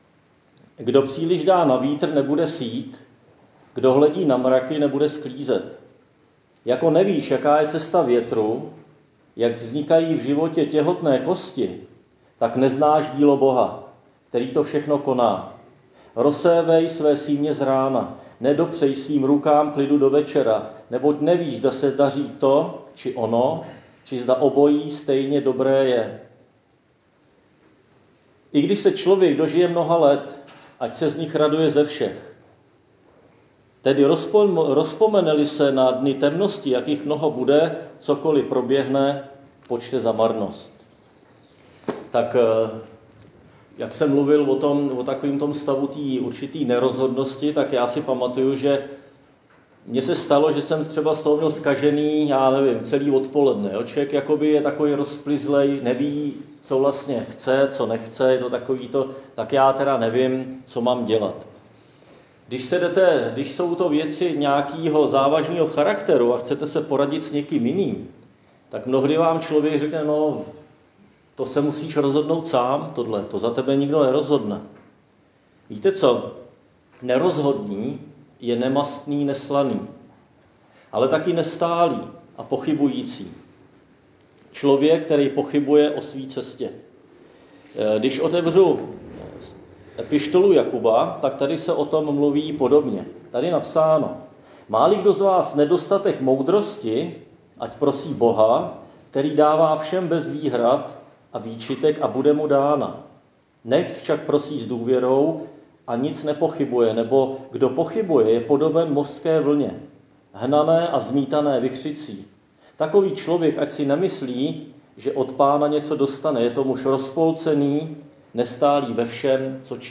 Křesťanské společenství Jičín - Kázání 2.5.2021